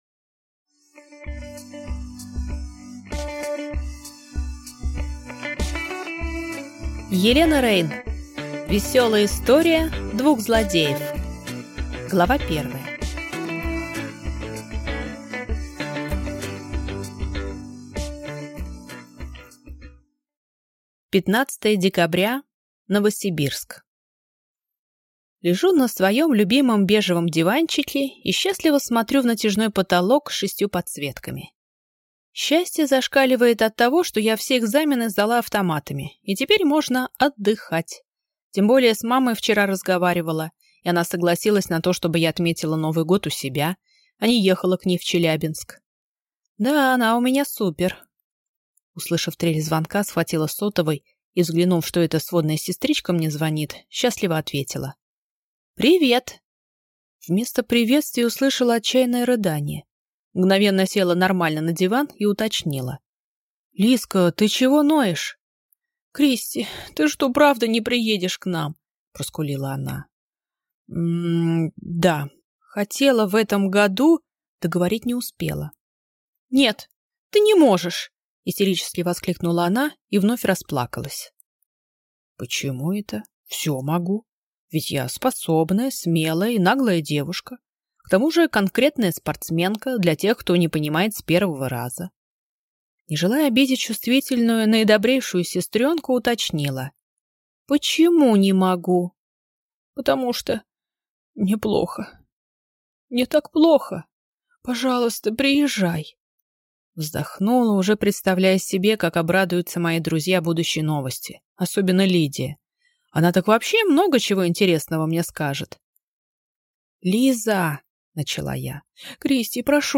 Аудиокнига Веселая история двух злодеев | Библиотека аудиокниг